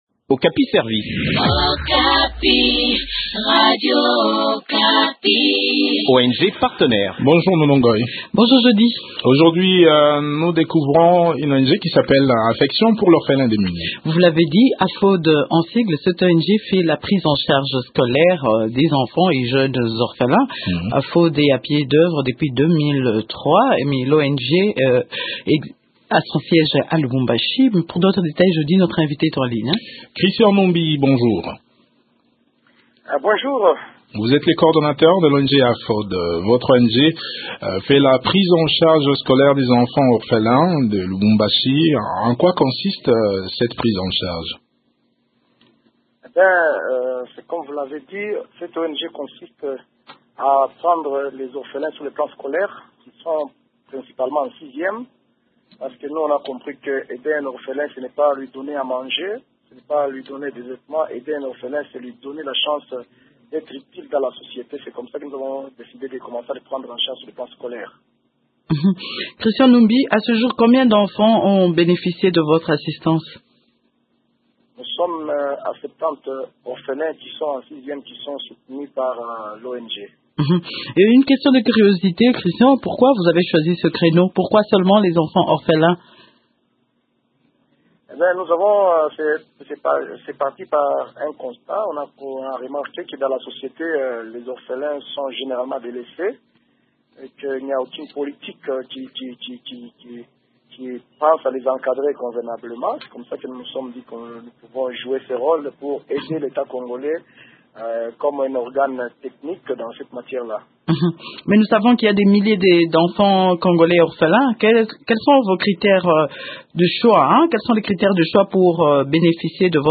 Le point sur ses activités dans cet entretien